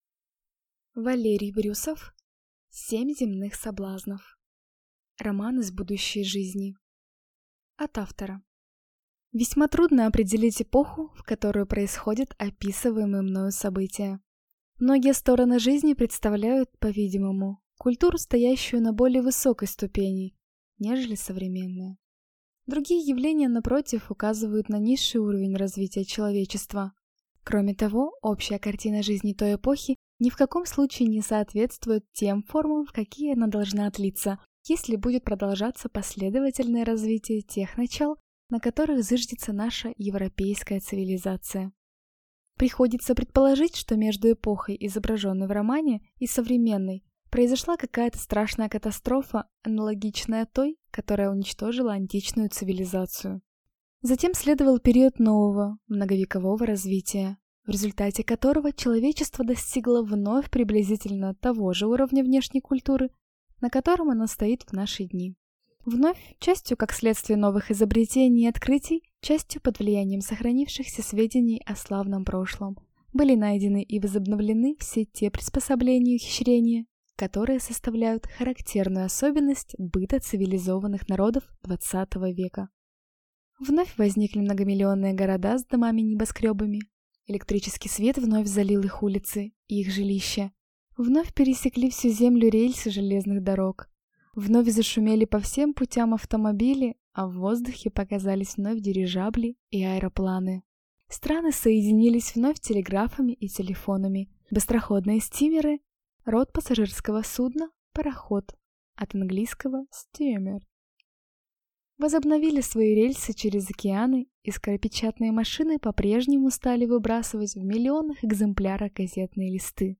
Аудиокнига Семь земных соблазнов | Библиотека аудиокниг